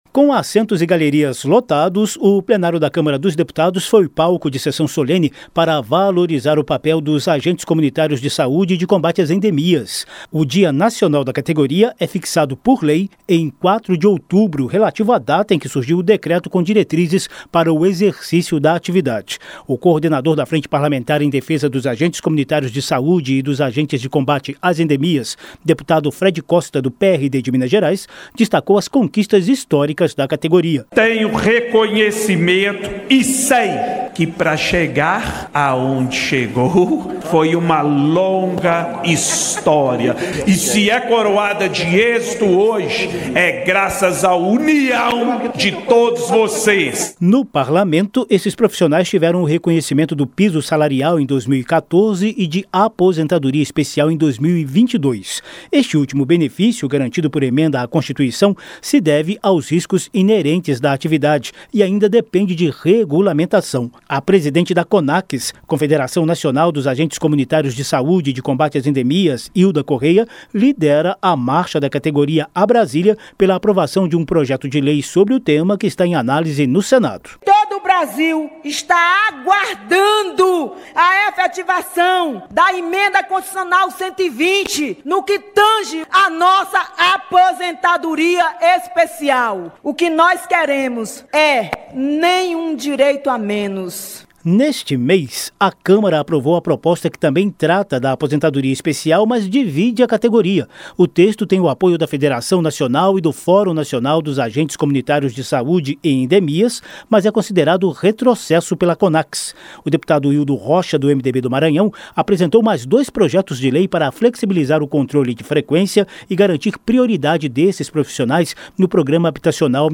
Com assentos e galerias lotados, o Plenário da Câmara dos Deputados foi palco de sessão solene nesta quinta-feira (23) para valorizar o papel dos agentes comunitários de saúde e de combate às endemias.